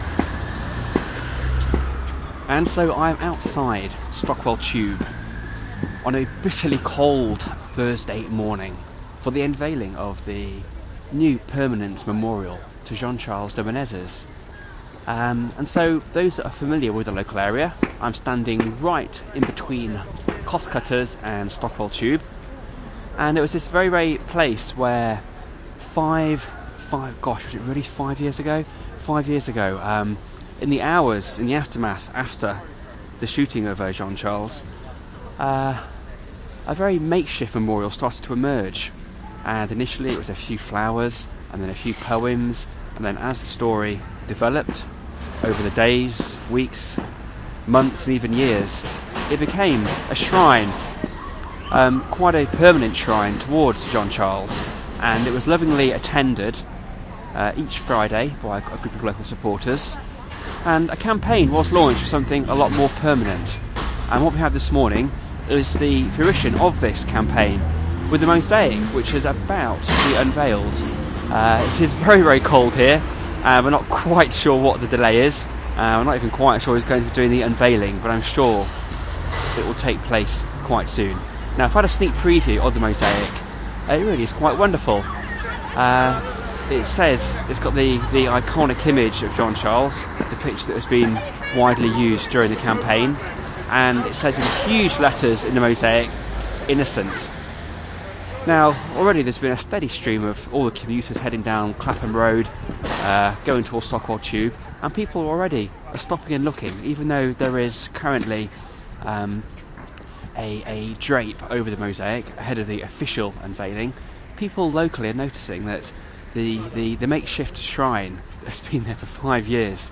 Unveiling of de Menezes memorial
89269-unveiling-of-de-menezes-memorial.mp3